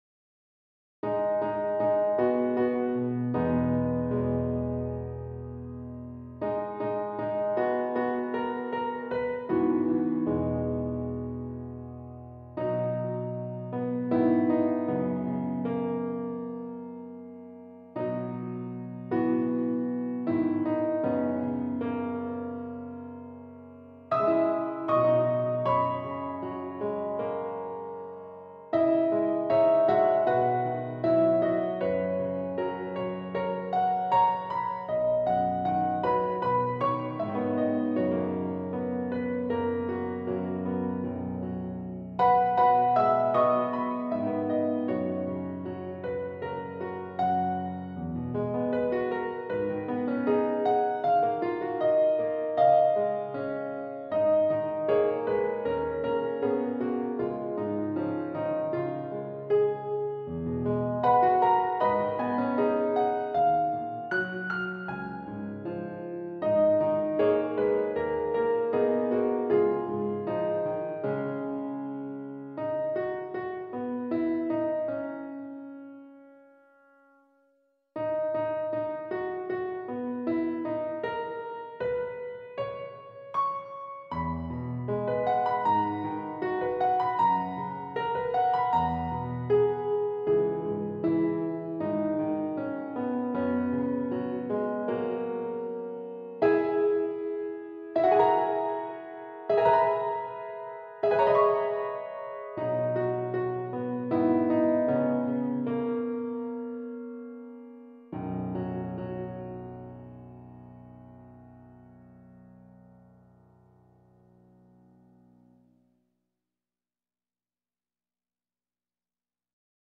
- Chant normalement à 4 voix mixtes SATB + piano
SATB Accompaniment Track